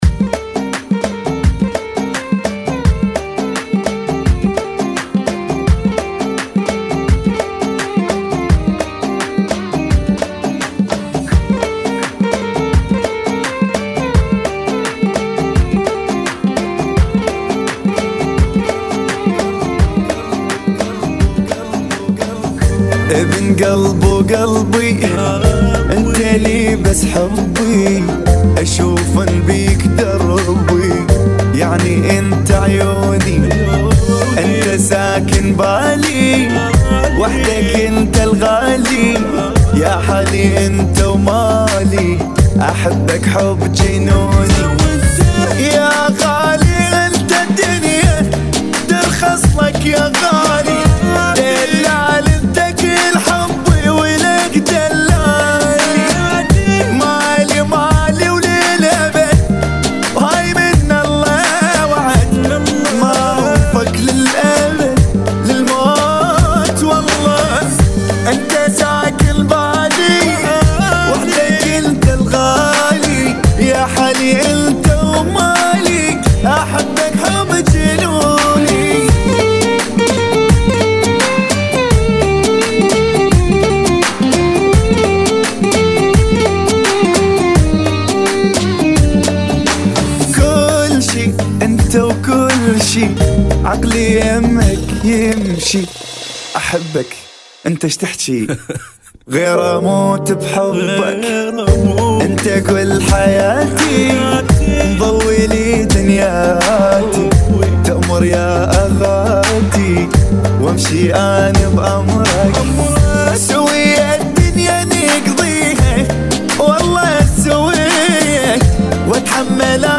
85 Bpm